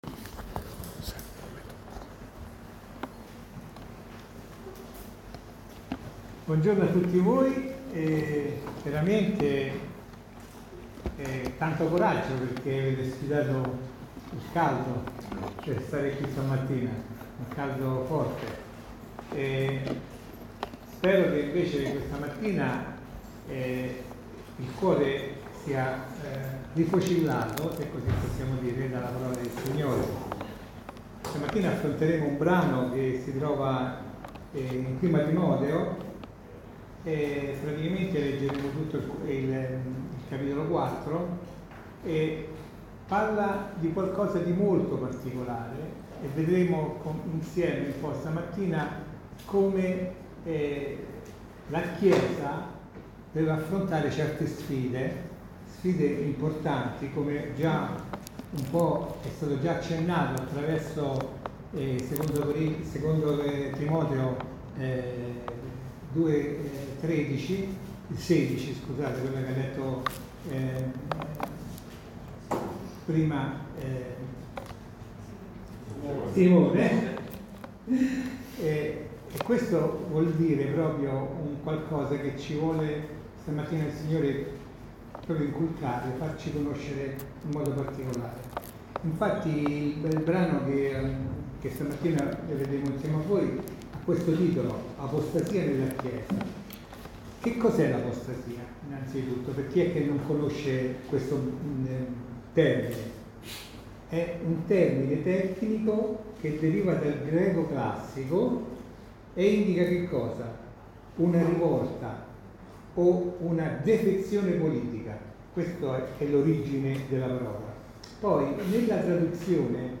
Insegnamenti biblici sul passo di 1 Timoteo 4:1-15.